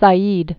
(sä-ēd)